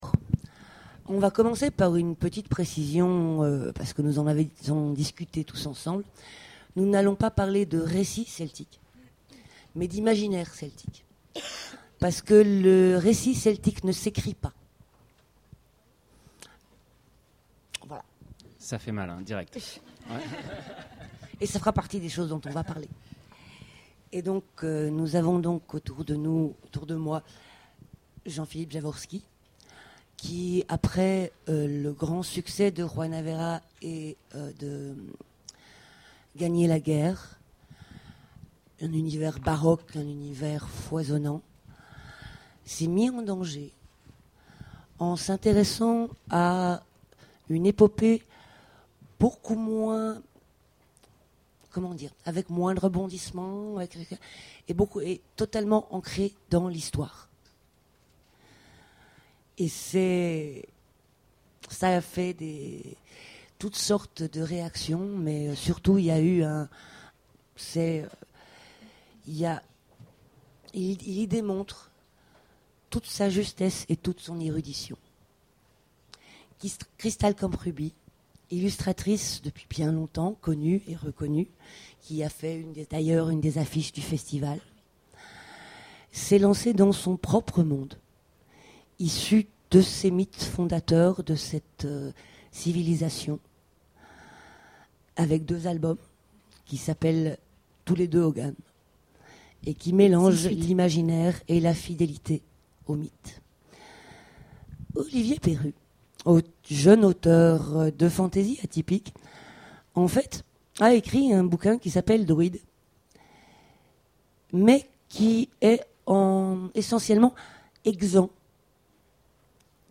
Imaginales 2014 : Conférence Récits celtiques